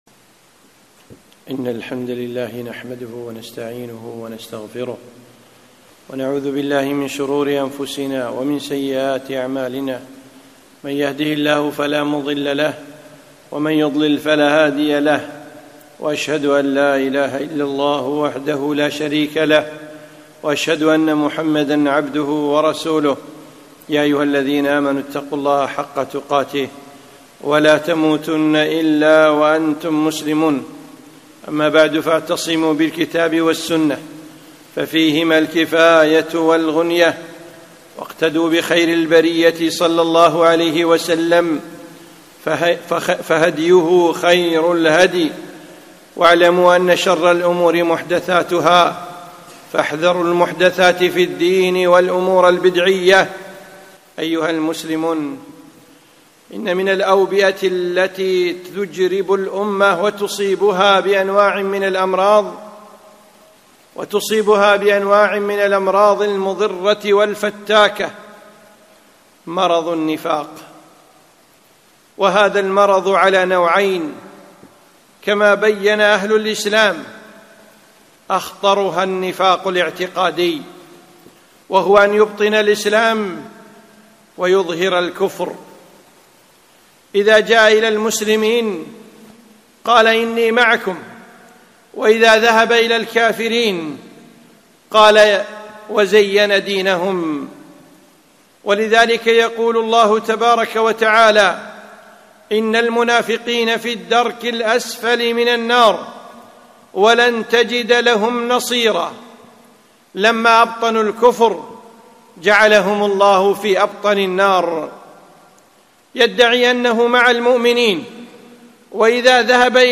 خطبة - احذروا النفاق